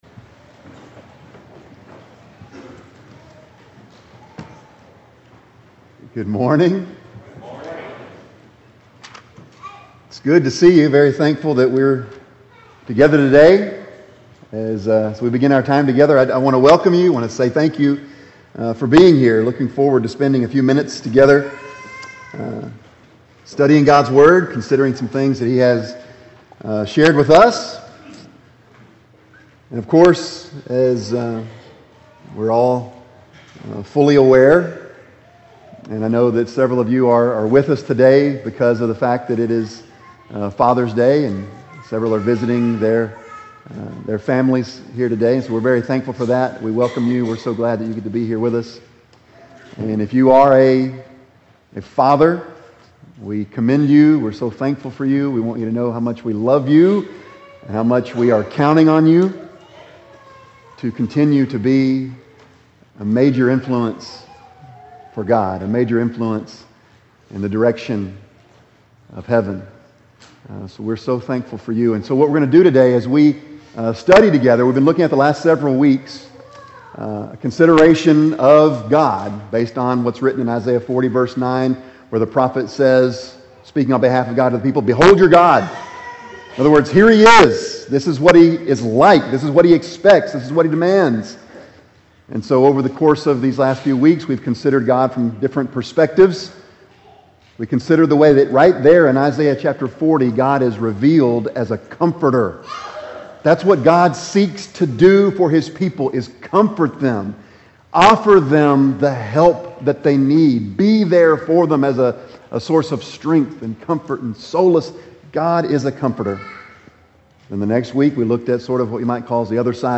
Bible Text: Matthew 6:9-13 | Preacher